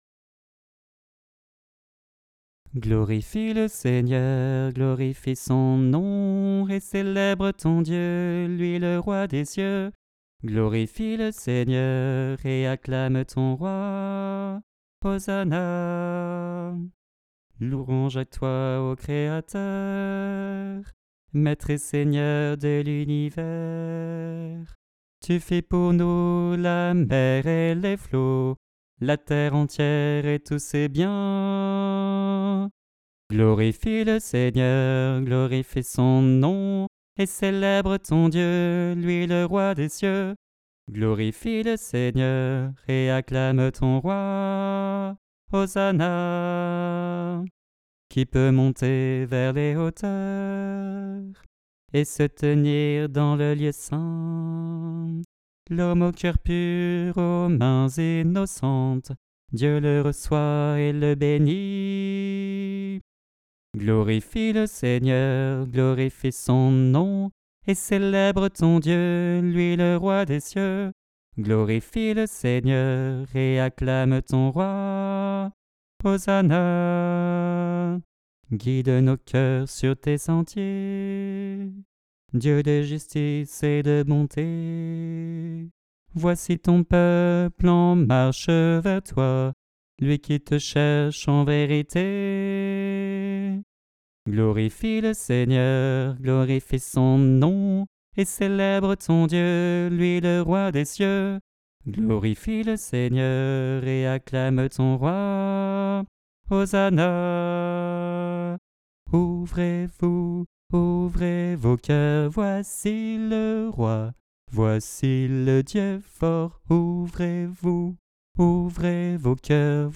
Voix chantée (MP3)COUPLET/REFRAIN
BASSE